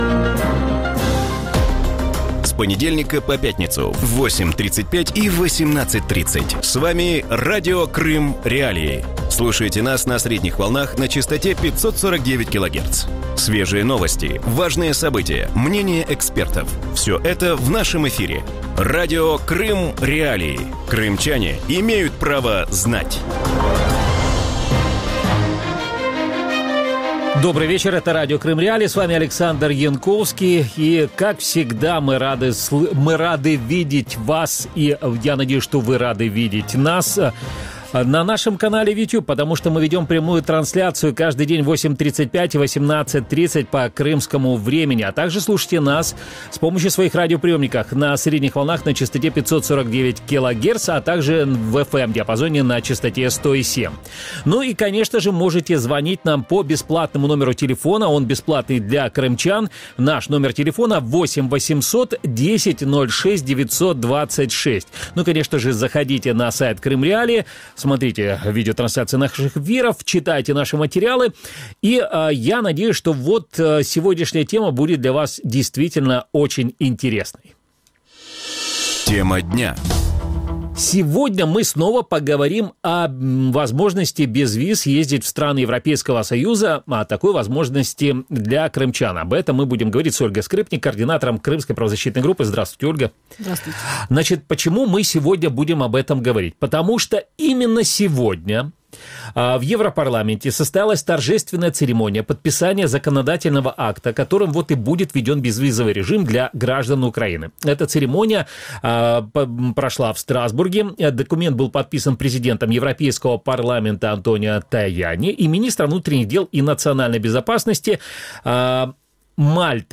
В вечернем эфире Радио Крым.Реалии обсуждают условия получения биометрических паспортов для крымчан и выезда в страны Евросоюза по безвизовому режиму. Как крымчанам получить биометрический паспорт в Украине?